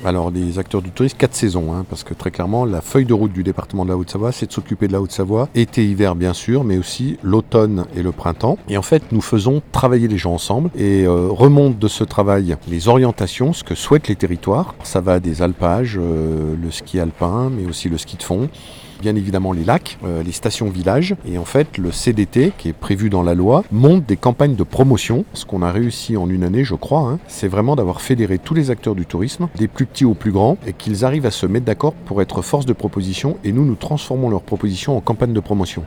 Son président Martial Saddier rappelle l’objectif et le fonctionnement de l’organisme dont 8 Conseillers départementaux sont notamment membres.